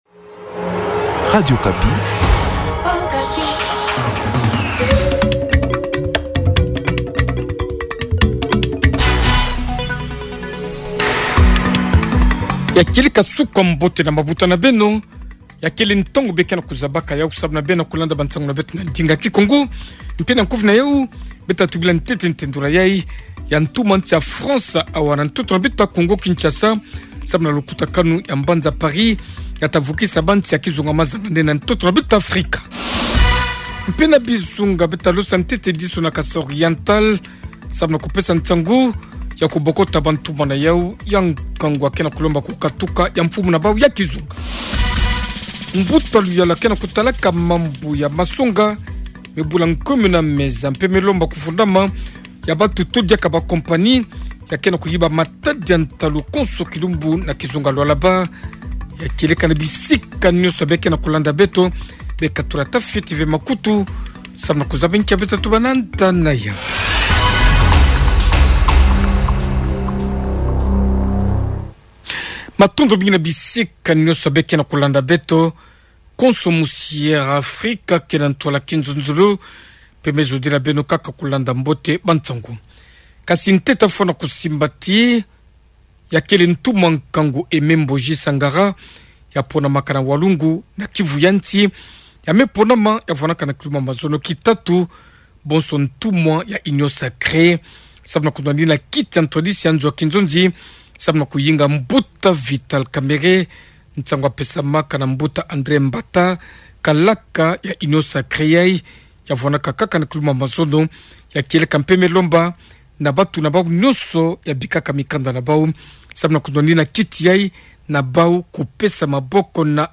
Journal Matin
Edition de ce matin 05h45''